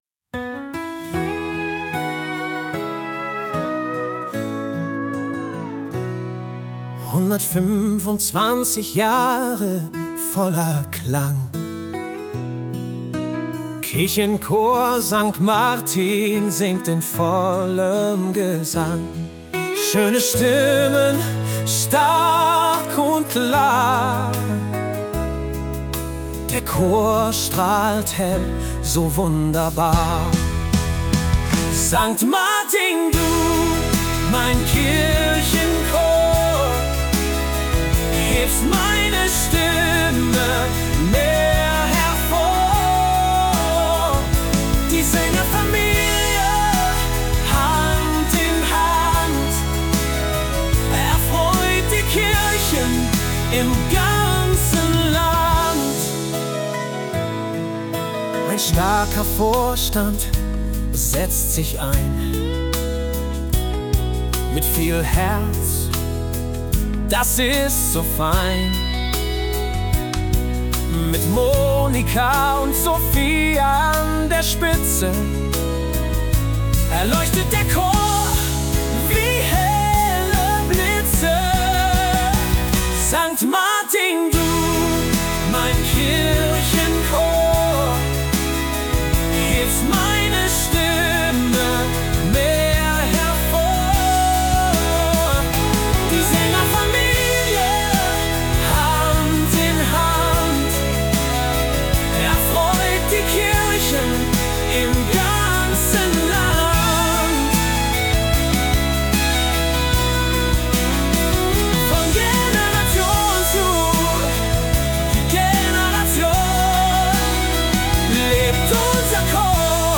mit Hilfe der künstlichen Intelligenz wurde der Liedertext zu einem Song vertont